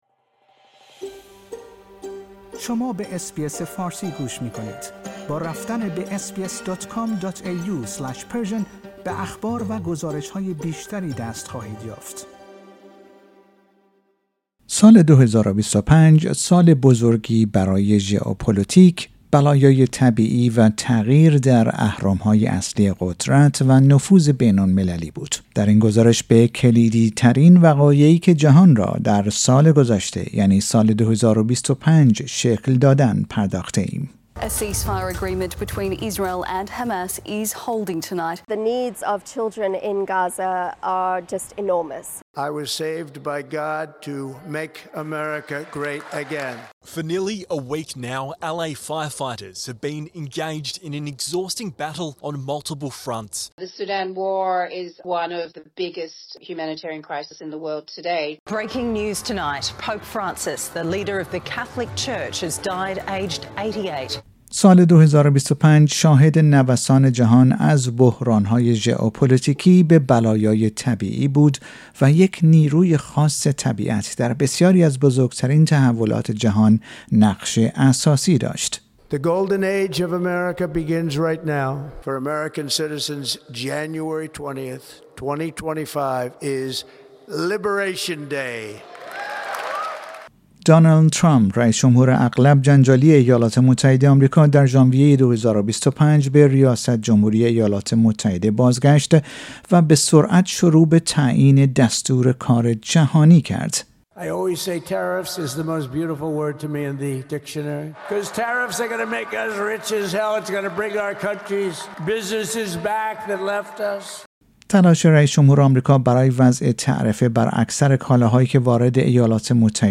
در این گزارش به کلیدی ترین وقایعی که جهان را در سال ۲۰۲۵ شکل دادند پرداخته ایم.